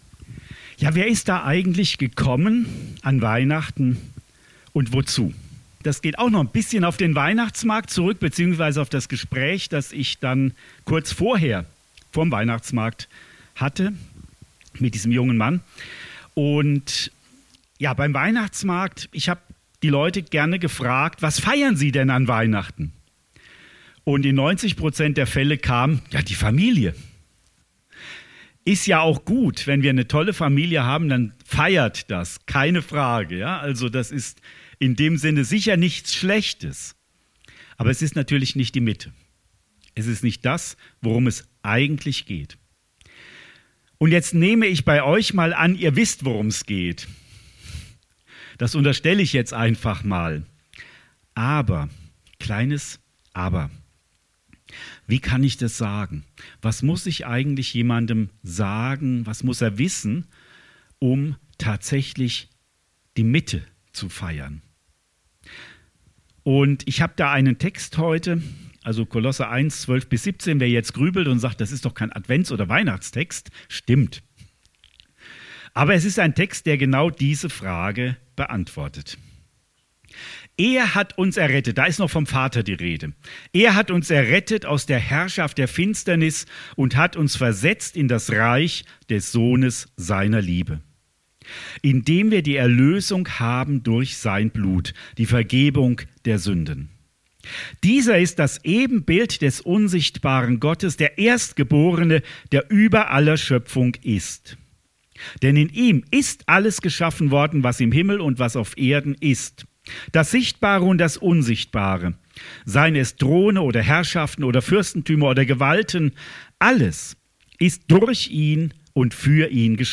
Predigt vom 15. Dezember 2024 – Süddeutsche Gemeinschaft Künzelsau